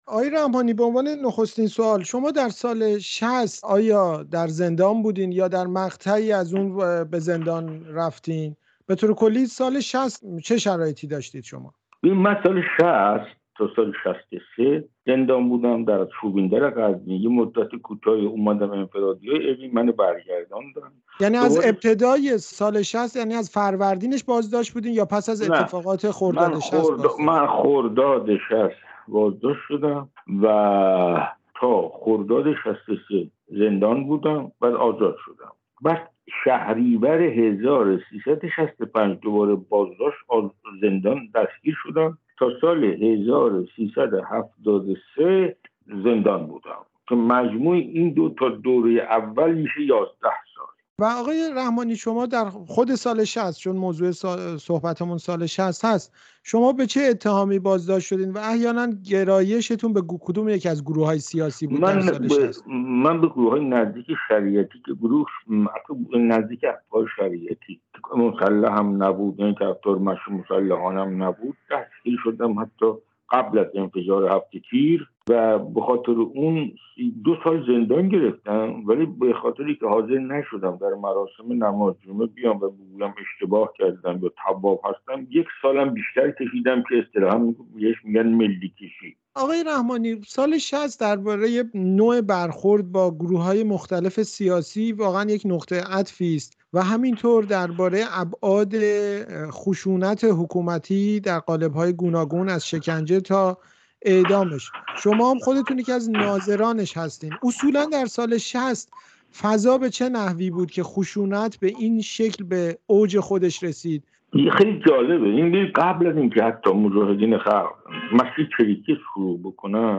گفت‌وگو با تقی رحمانی درباره اعدام‌های سال ۱۳۶۰